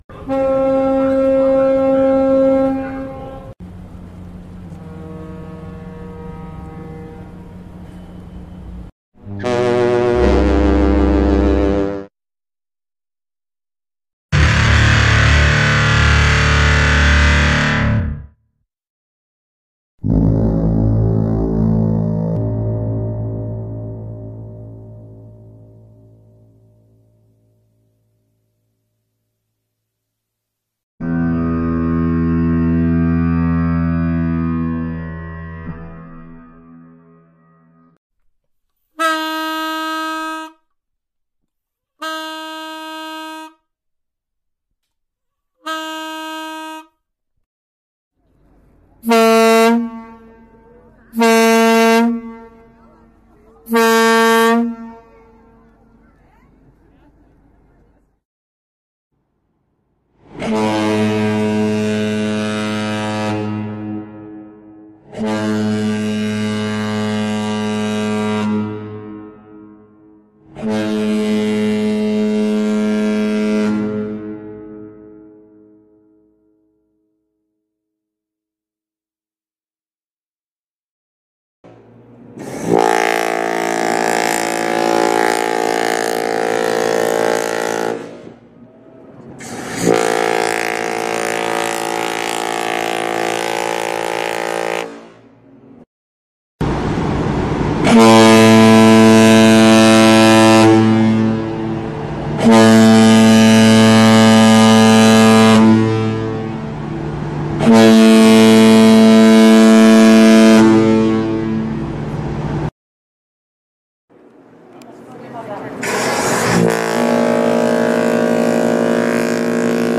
دانلود آهنگ بوق کشتی 4 از افکت صوتی حمل و نقل
جلوه های صوتی
دانلود صدای بوق کشتی 4 از ساعد نیوز با لینک مستقیم و کیفیت بالا